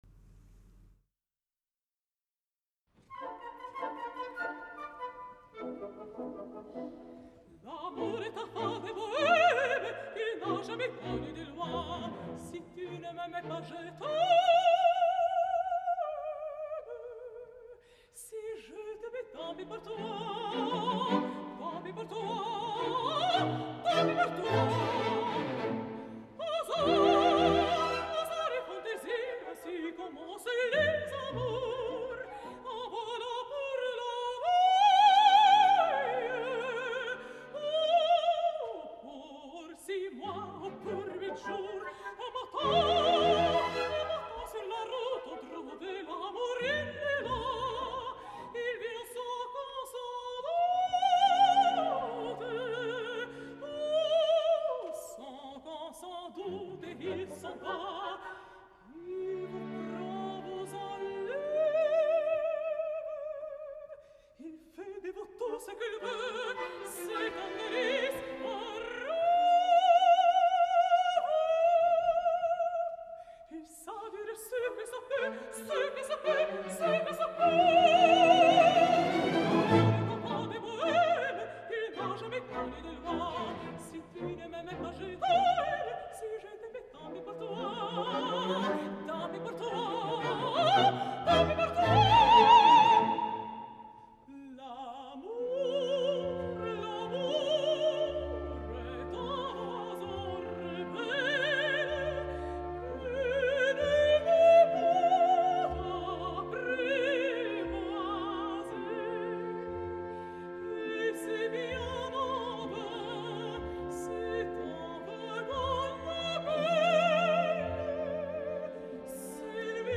La magnífica i enlluernadora mezzosoprano letona
El passat 12 de juny de 2012 ambdós van oferir un concert a la sala Philharmonie im Münchner Gasteig, amb la Münchner Rundfunkorchester.
Vocalment Garanča està esplendorosa, res a dir, ans al contrari, i per a mi ens ofereix tres moments de veritable interès. les àries de La donzella d’Orleans de Txaikovski, la de Charles Gounod provinent de l’òpera La reina de Saba, i sobretot “L’amour est enfant de bohème” que era l’ària original d’entrada de Carmen, i que Bizet va substituir per la havanera provinent del “arreglito” de Iradier, molt més seductora.